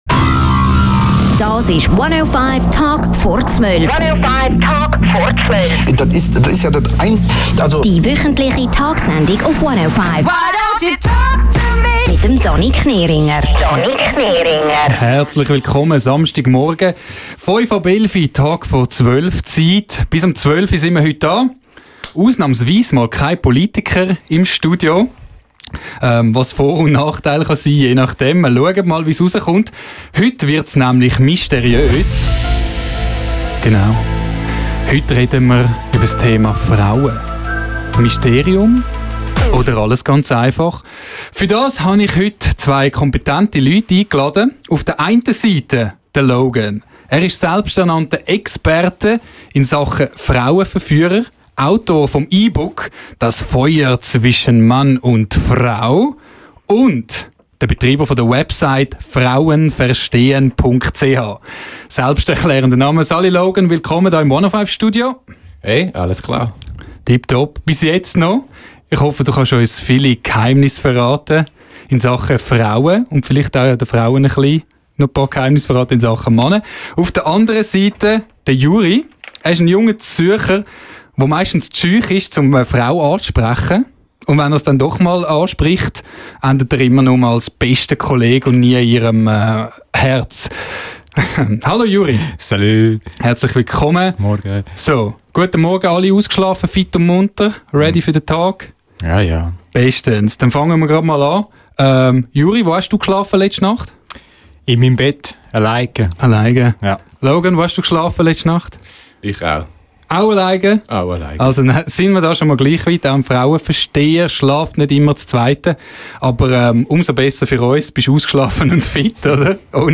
Vielleicht versteht ihr ja das Schwiizerdütsch… 😉